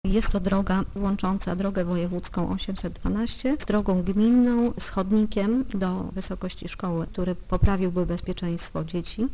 - Decyzji o podziale dotacji z Funduszu Dróg Samorządowych jeszcze nie ma, ale nasz wniosek wstępnie został zakwalifikowany do dofinansowania. Czekamy na potwierdzenie przyznania rządowych środków, które przeznaczymy na przebudowę odcinka o długości prawie 900 metrów - mówi wójt gminy Krasnystaw Edyta Gajowiak-Powroźnik: